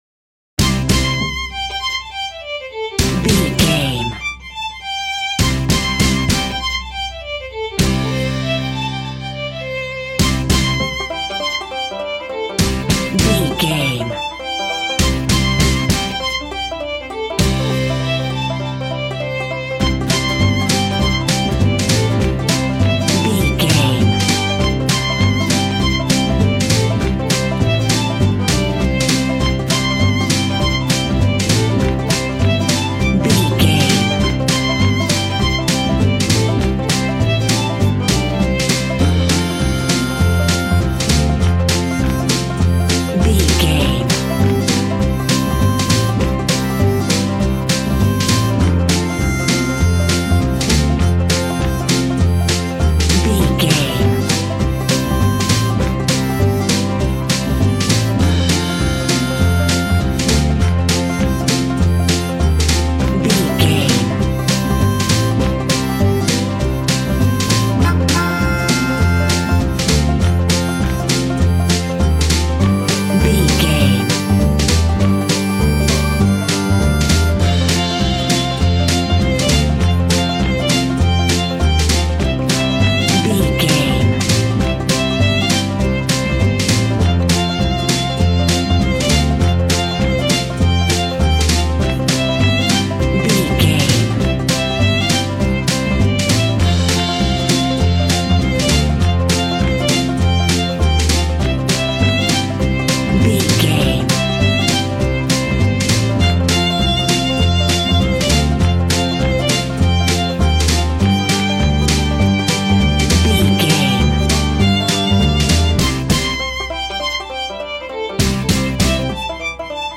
Fun and upbeat American fiddle music from the country.
Ionian/Major
Fast
bouncy
positive
double bass
drums
acoustic guitar